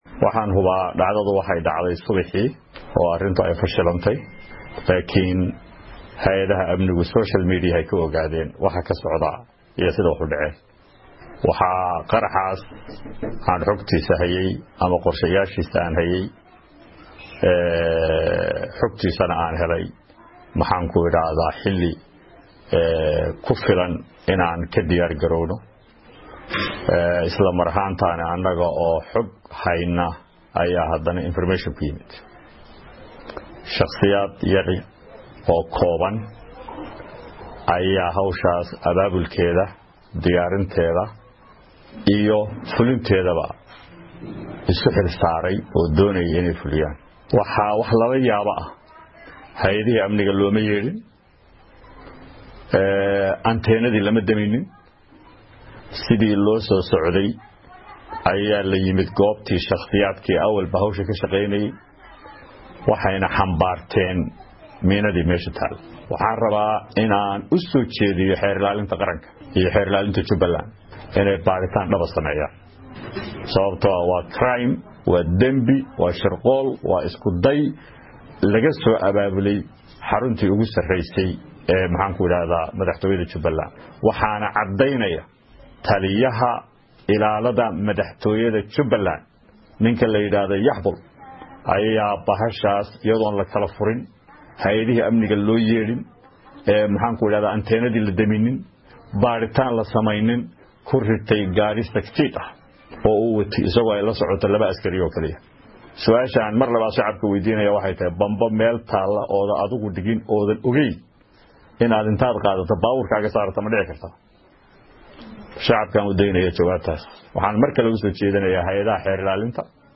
Hoos ka dhageyso codka xildhibaan Xidig.
Cod-Xildhibaan-Cabdi-Rashiid-xidig.mp3